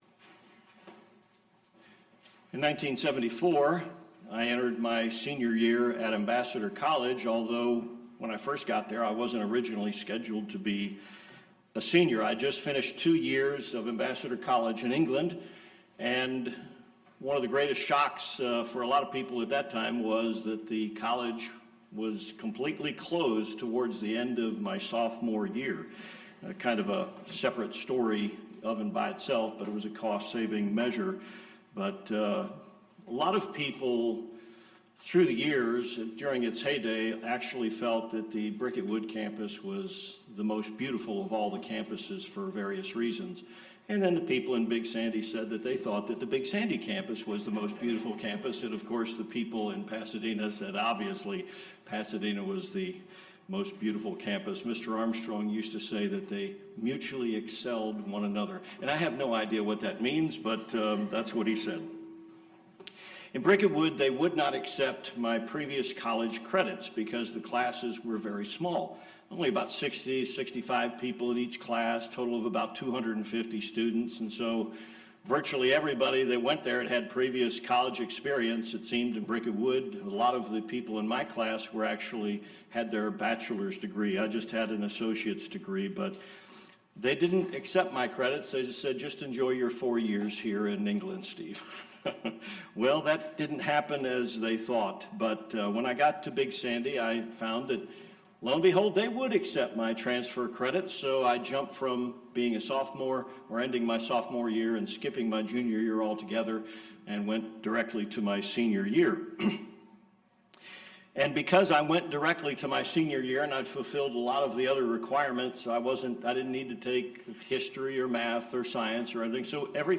Sermons
Given in El Paso, TX